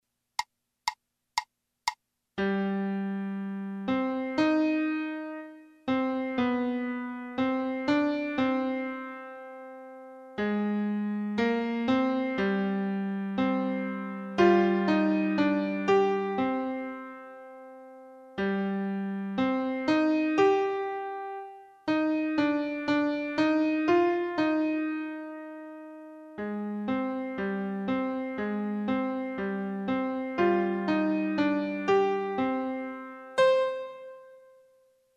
연습